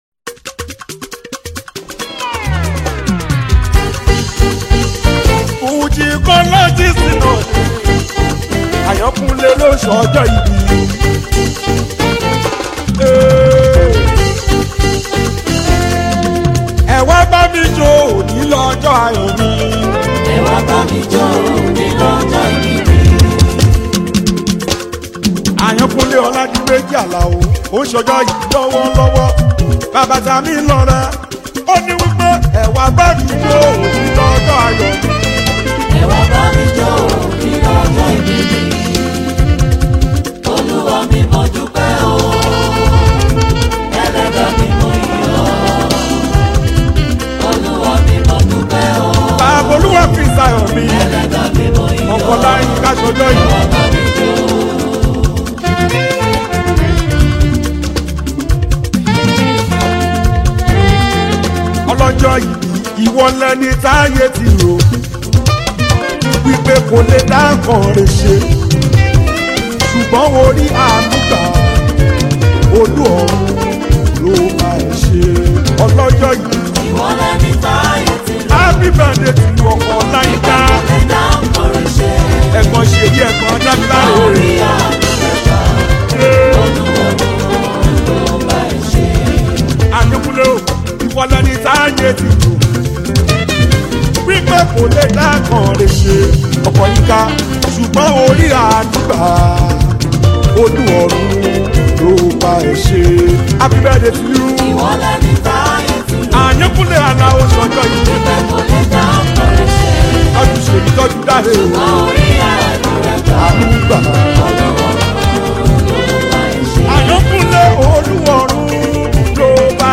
Nigerian Yoruba Fuji track
be ready to dance to the beats